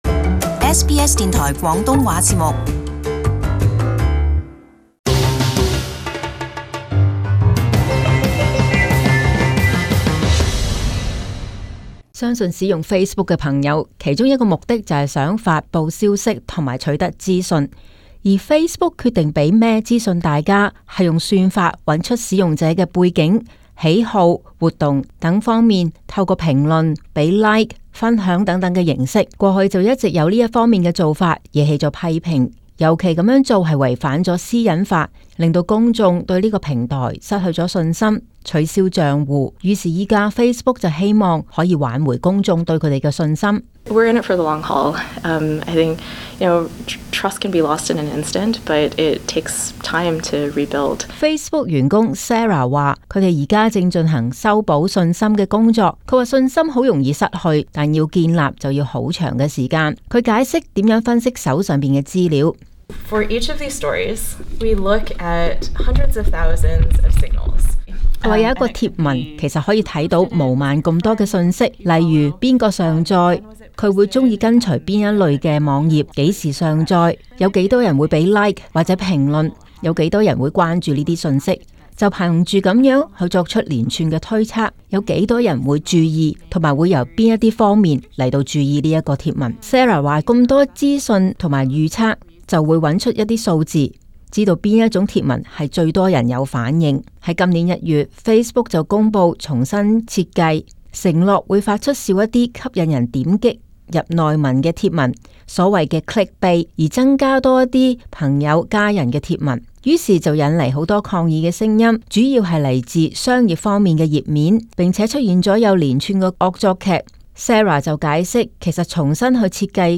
【时事报导】Facebook希望重建用户信心